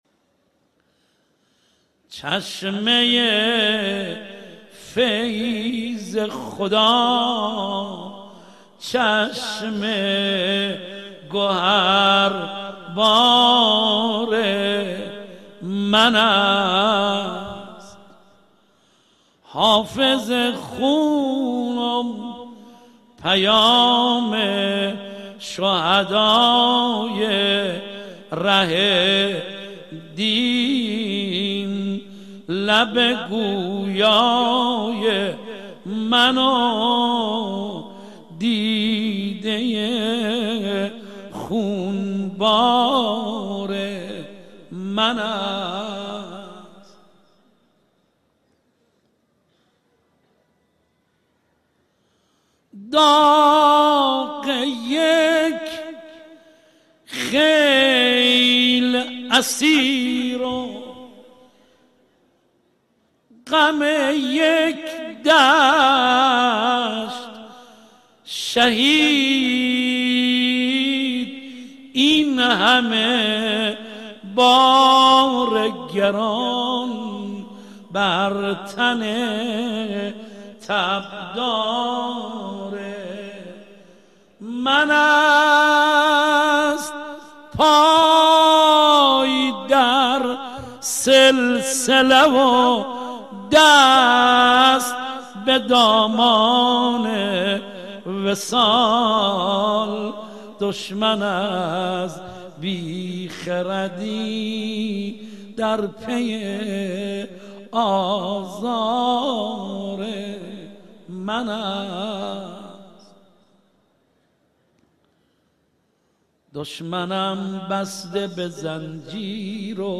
مداحی و مرثیه خوانی بمناسبت شهادت امام سجاد علیه السلام